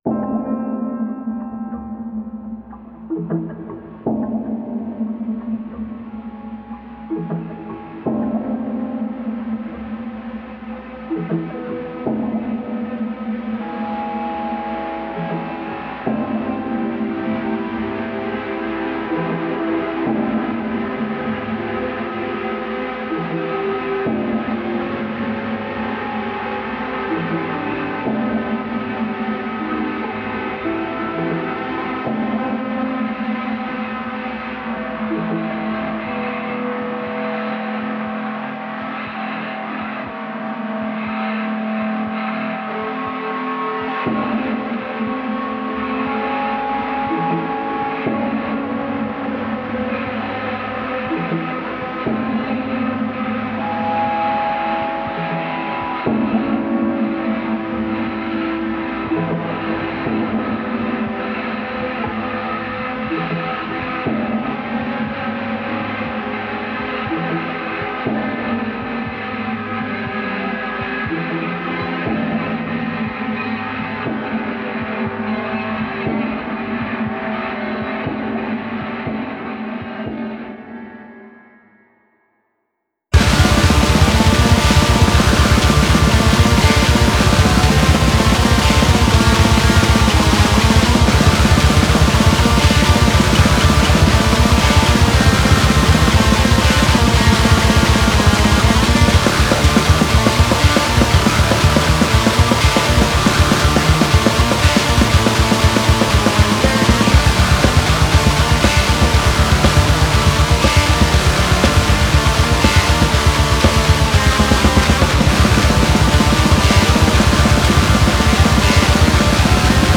Ich hatte mir vorgenommen diesen Winter mal wieder ein bisschen Black Metal zu machen.
Dazu auch noch kleines Update: Ich hab ein halbes Album als Demos irgendwie rough zusammengejammt und dann kam von meinen Kollegen nicht mehr viel Initiative.